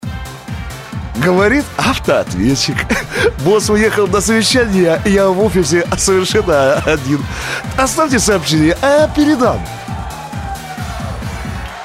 ** Приветствующие сообщения для автоответчика **